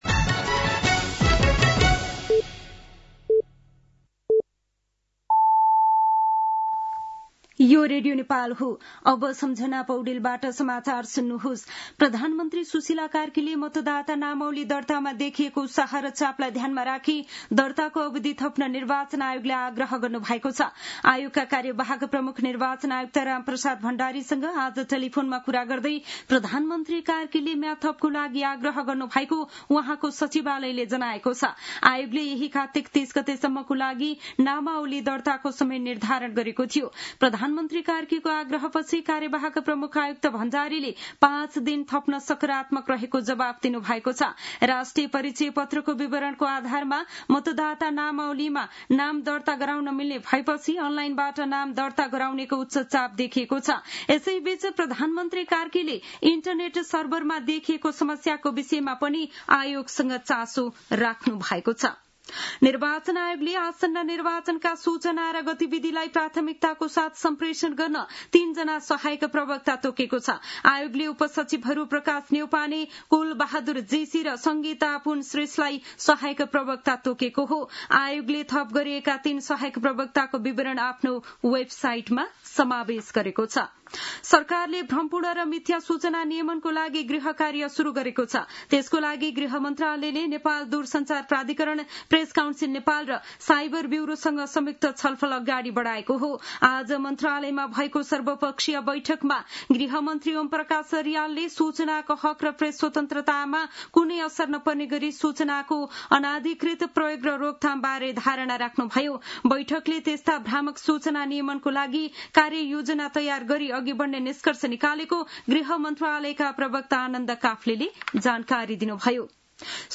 साँझ ५ बजेको नेपाली समाचार : २८ कार्तिक , २०८२
5-pm-nepali-news-7-28.mp3